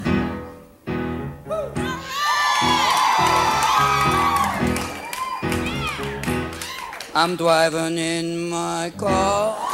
/Caw
caw.mp3